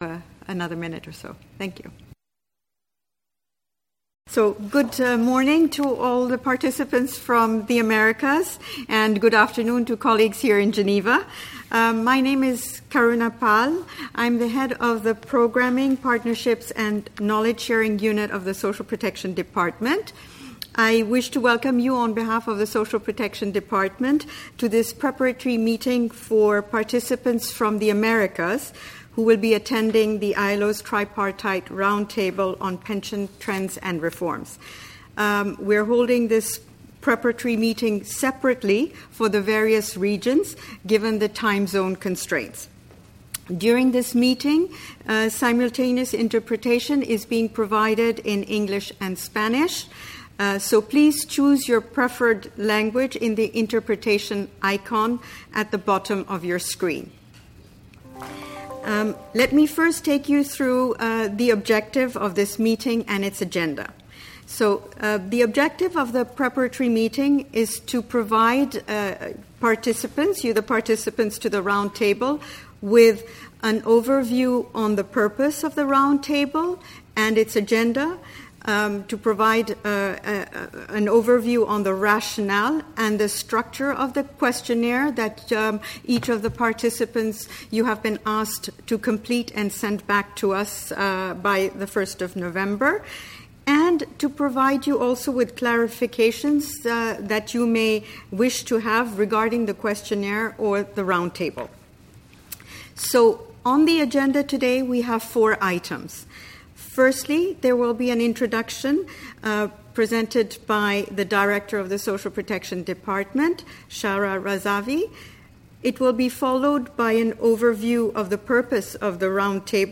A separate preparatory meeting for the round table was organized for each of the regions on 21 and 22 October 2020. The aim of the preparatory meetings was to provide, amongst others, an overview of the purpose of the round table and its agenda, to explain the rationale of the questionnaire and its structure, and to provide clarifications requested by participants to the round table.
Disclaimer: The interpretation of proceedings serves to facilitate communication and does not constitute an authentic or verbatim record of the proceedings.